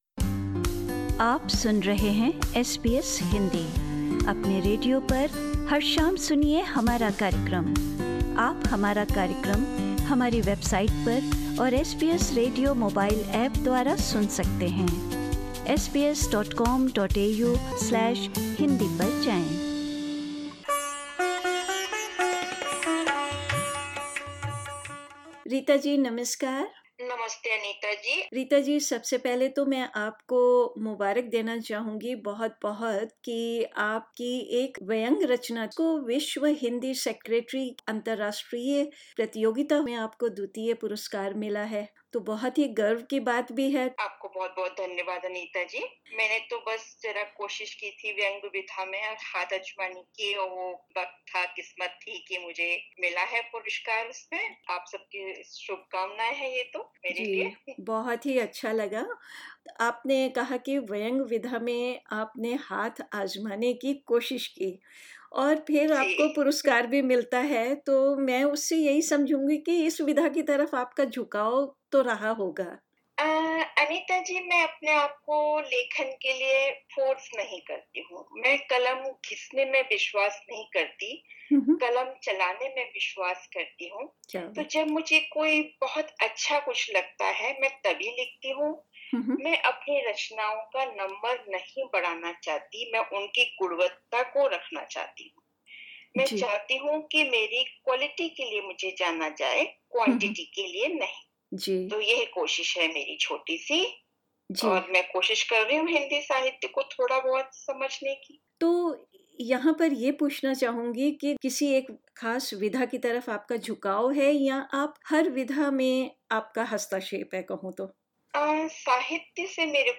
इस बातचीत में, वह हिन्दी साहित्य में रुचि और अपनी एक व्यंग्य रचना साझा कर रही हैं।